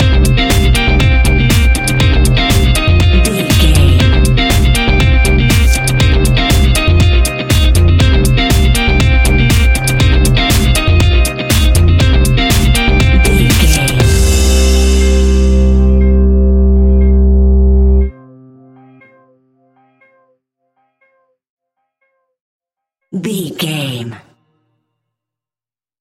Aeolian/Minor
intense
energetic
repetitive
electric guitar
bass guitar
drums
synthesiser
drum machine
piano
funky house
electronic funk
upbeat
synth leads
Synth Pads
synth bass